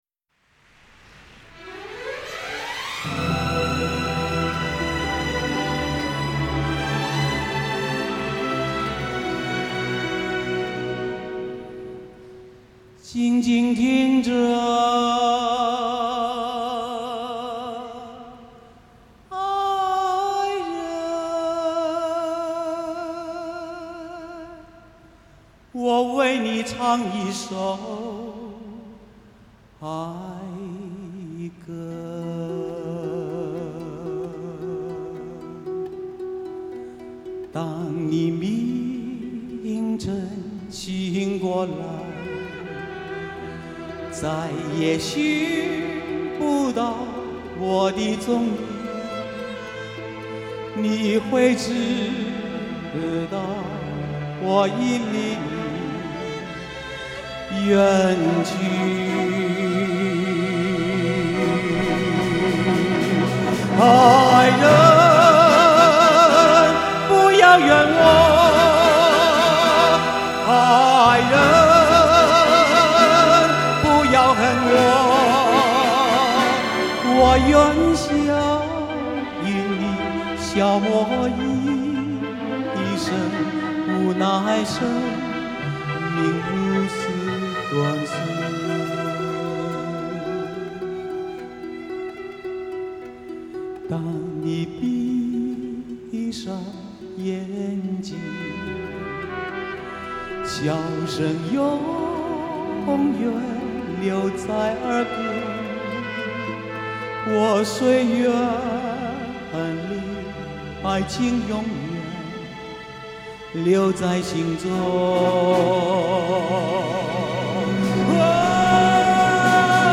咬字清晰准确